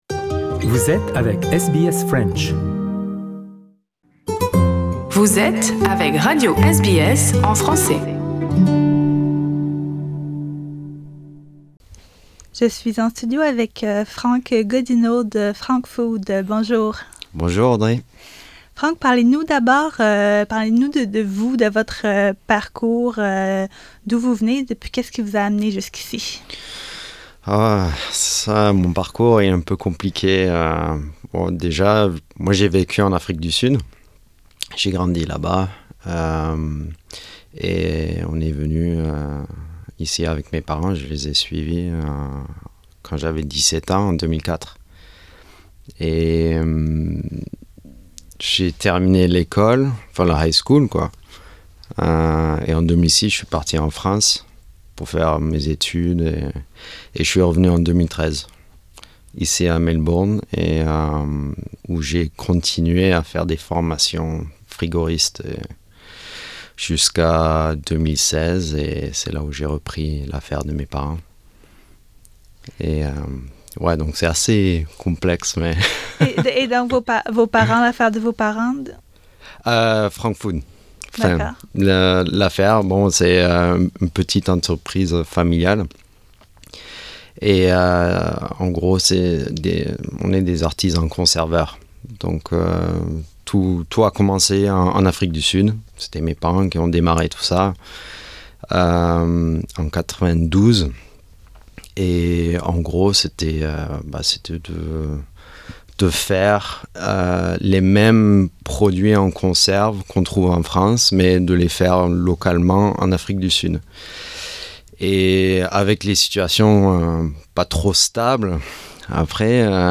est venu nous rendre en studio